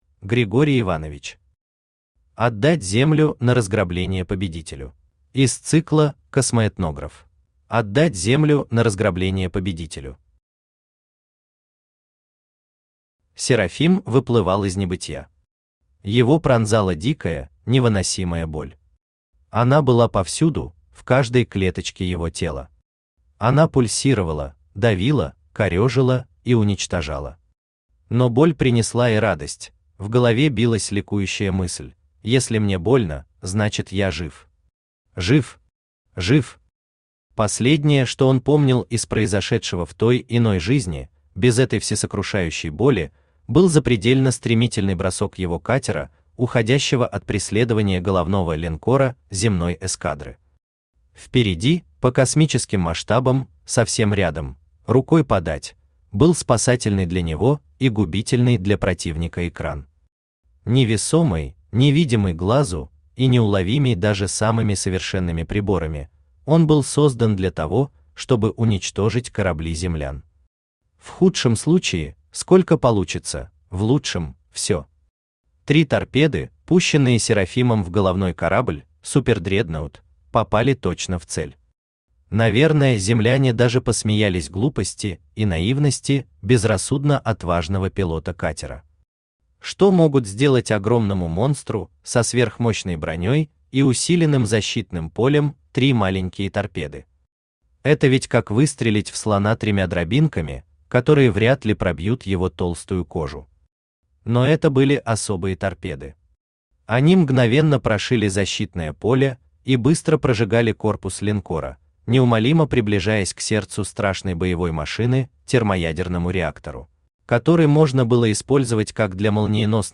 Читает: Авточтец ЛитРес
Аудиокнига «Отдать Землю на разграбление победителю…».